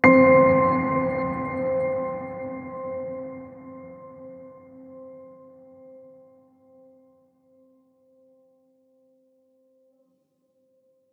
piano4.wav